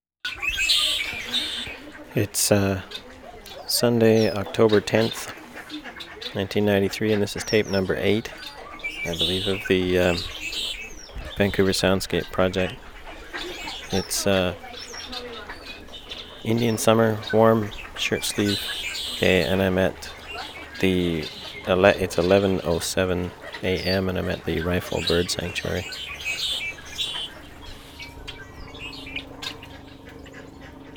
tape ID